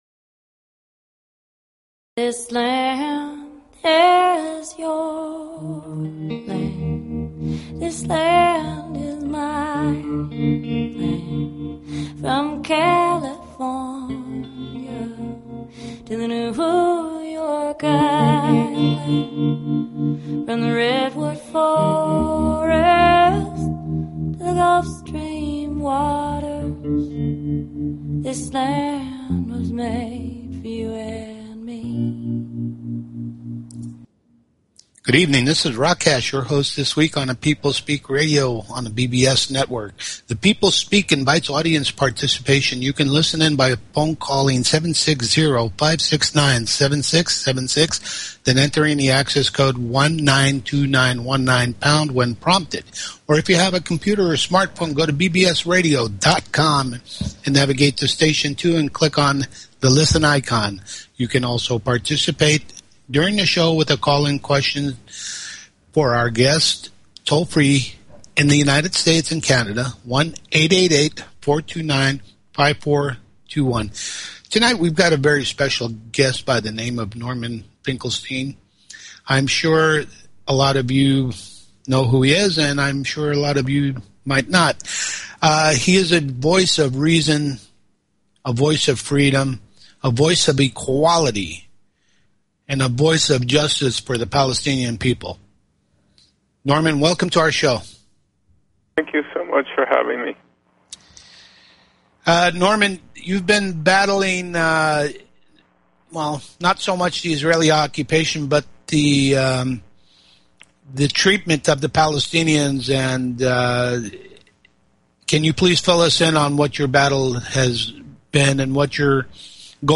Talk Show Episode
Guest, Norman Finkelstein